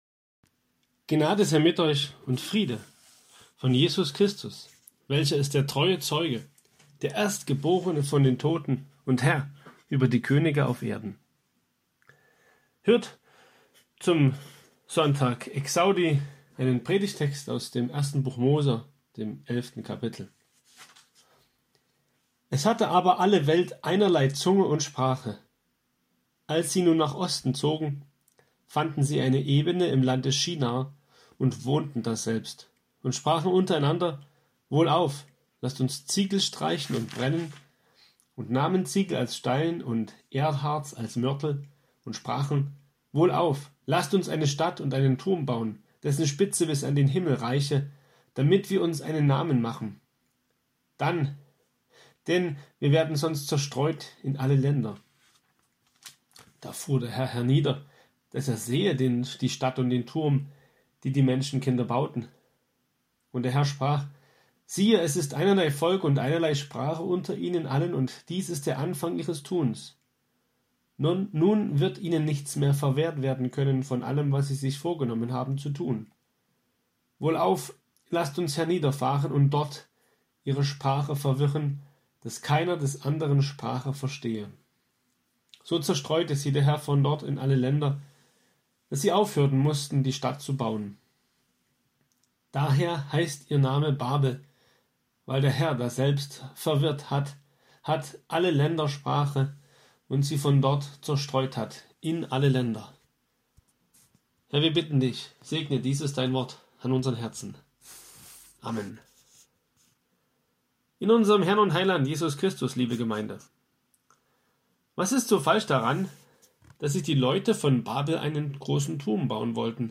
Predigt Exaudi 2020.mp3